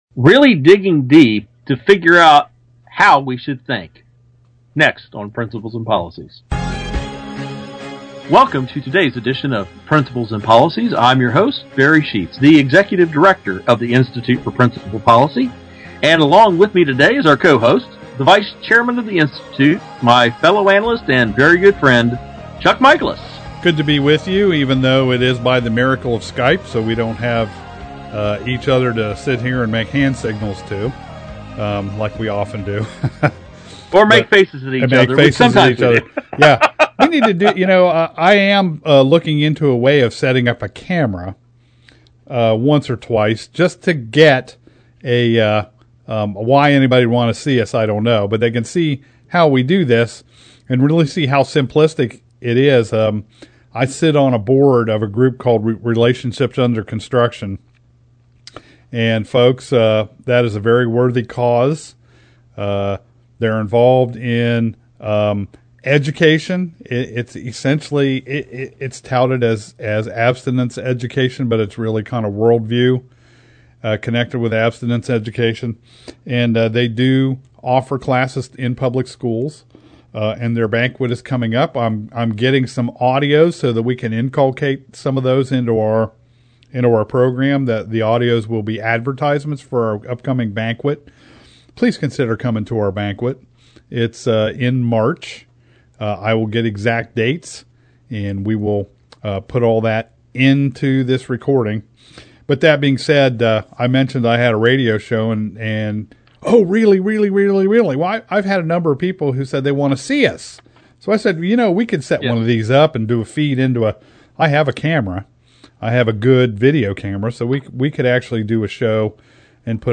Our Principles and Policies radio show for Saturday August 31, 2013.